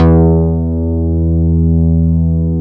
Index of /90_sSampleCDs/Roland LCDP02 Guitar and Bass/BS _Synth Bass 1/BS _MIDI Bass